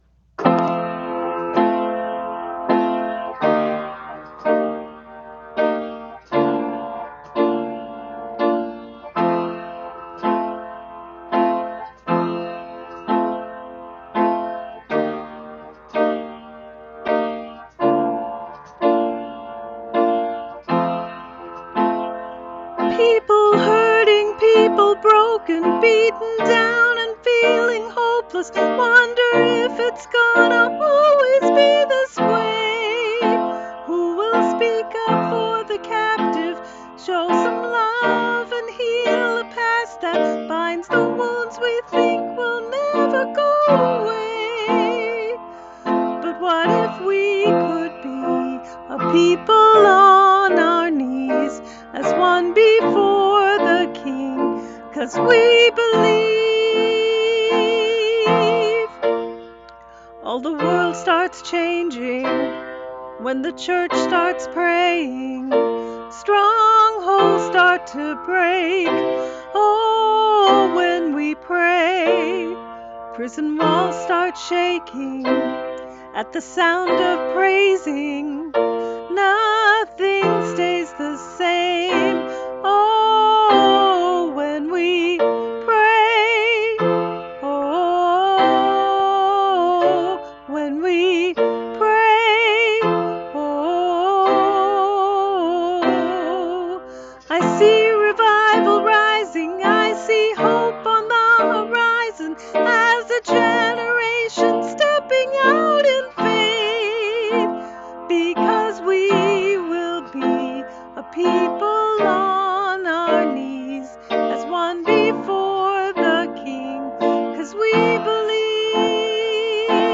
I recorded this for a Sunday morning service when I wasn’t sure I was going to have the voice to sing. It is a strong statement about what happens when the Church prays.
Keyboard